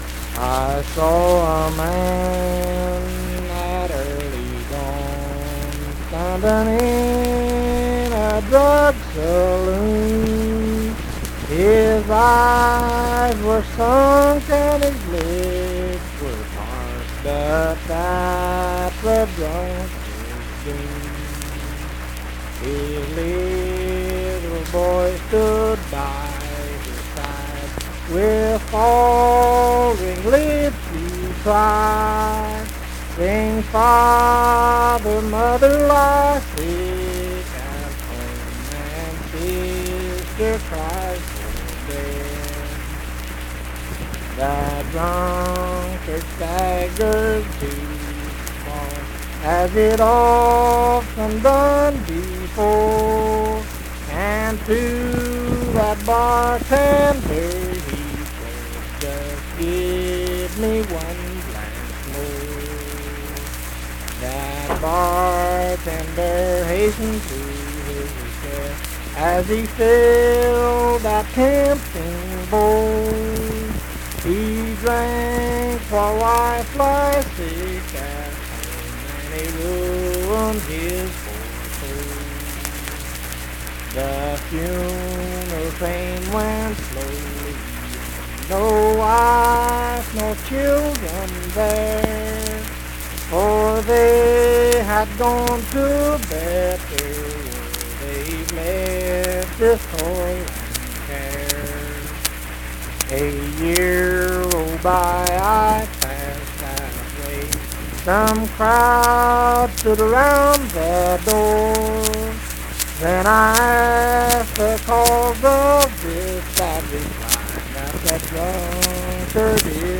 Unaccompanied vocal music
Verse-refrain 6(4).
Voice (sung)